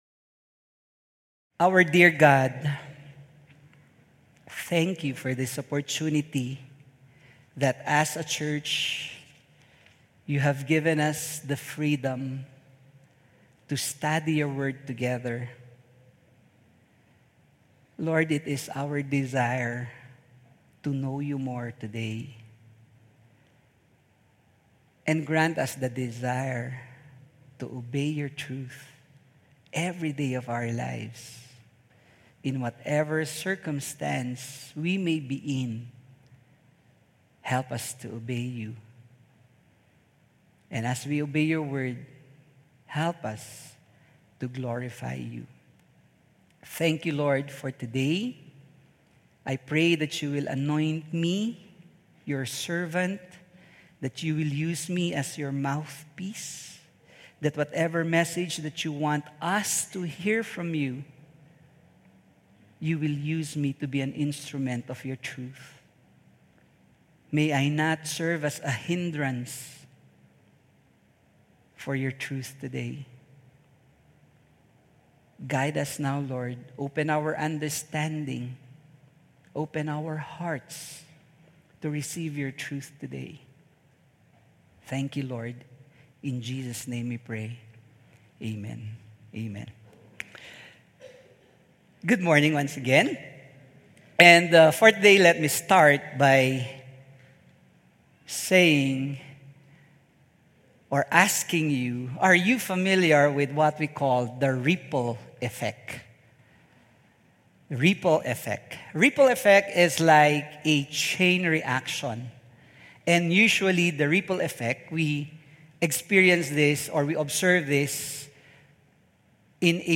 WATCH AND BE BLESSED LISTEN AND BE BLESSED Be Still, For God is Our Refuge 1 Videos March 15, 2026 | 9 A.M Service Be Still, For God is Our Refuge | Psalm 46:1–11 Information Information Download the Sermon Slides here.